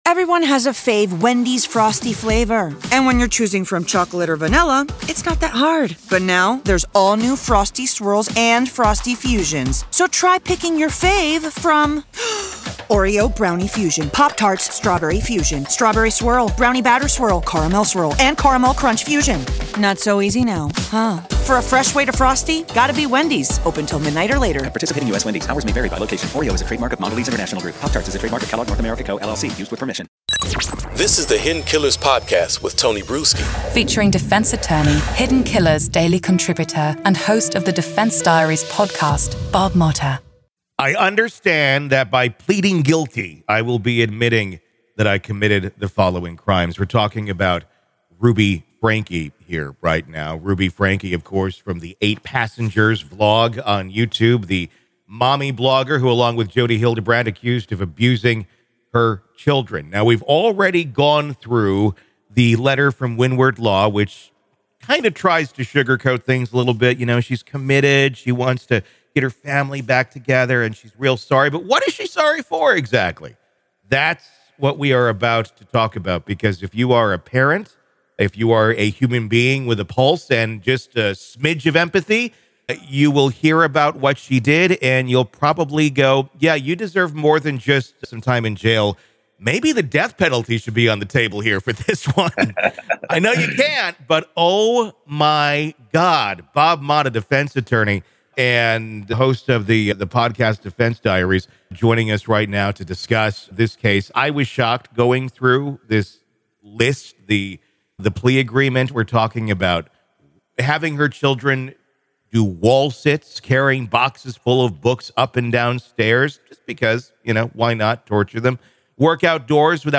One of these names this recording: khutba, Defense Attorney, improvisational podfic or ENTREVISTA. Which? Defense Attorney